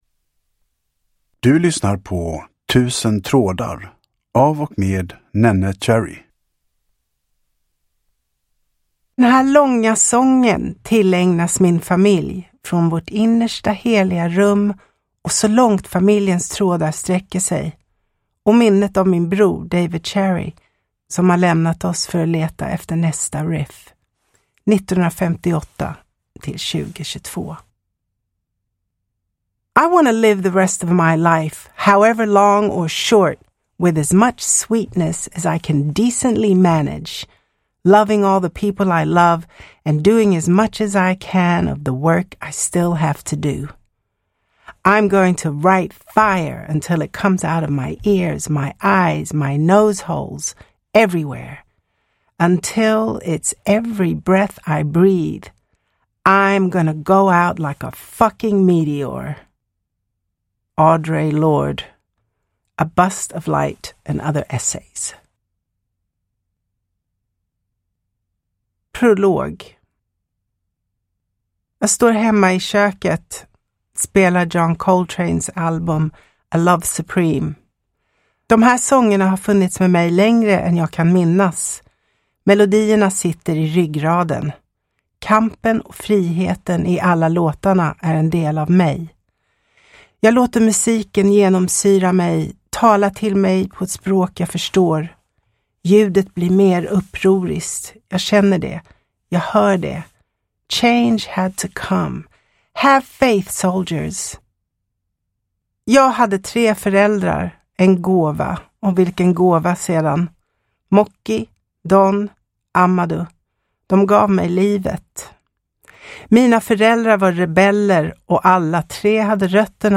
Tusen trådar – Ljudbok
Uppläsare: Neneh Cherry